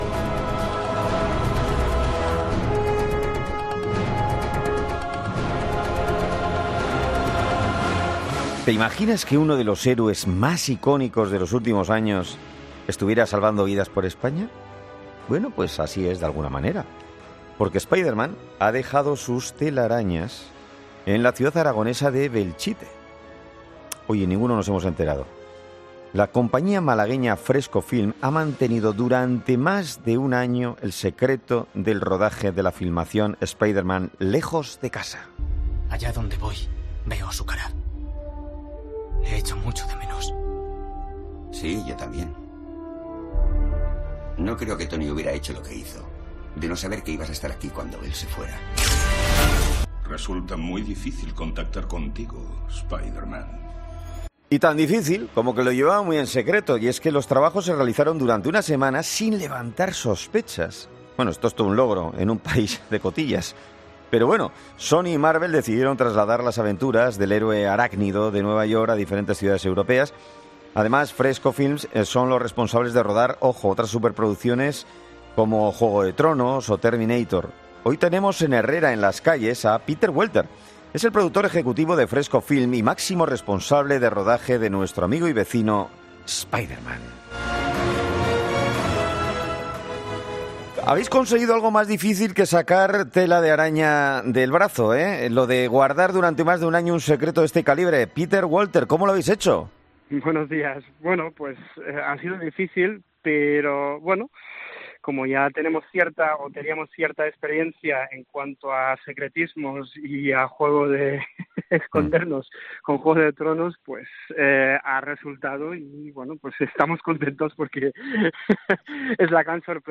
Con Carlos Herrera